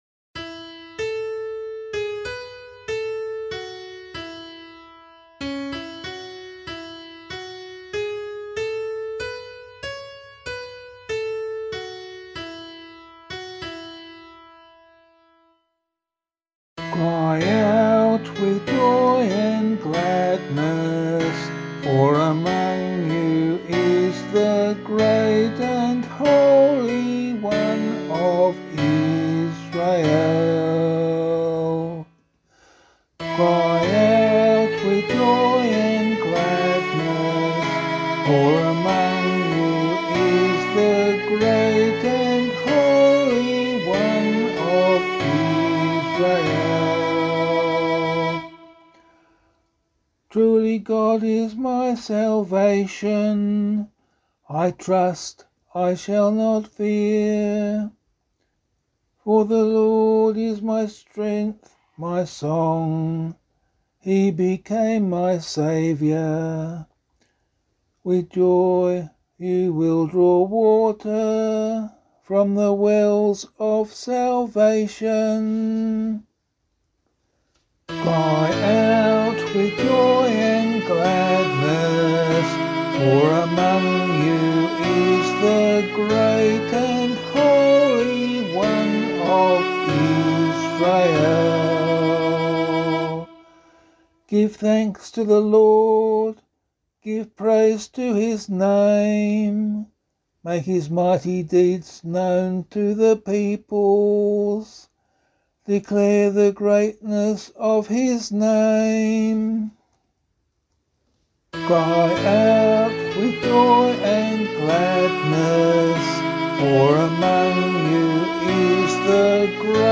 003 Advent 3 Psalm C [LiturgyShare 8 - Oz] - vocal.mp3